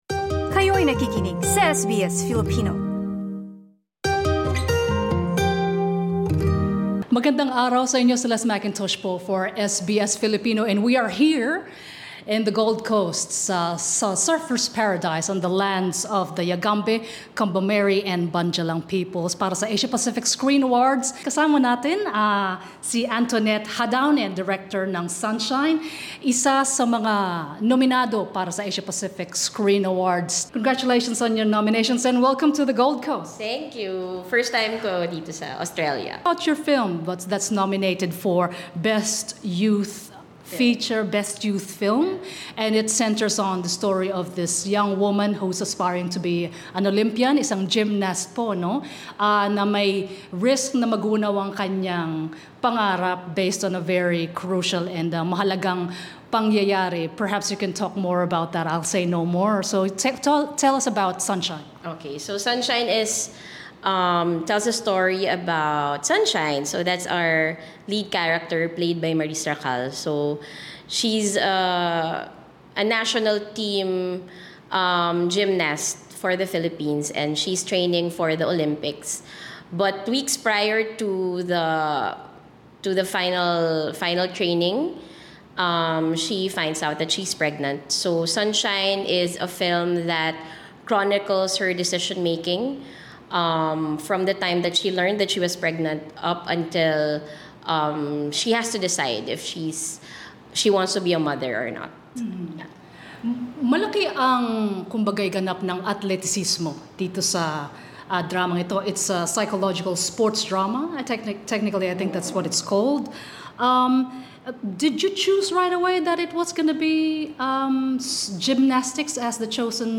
Nakapanayam ng SBS Filipino ang direktor na si Antoinette Jadaone na dumalo sa awards night sa Gold Coast Queensland.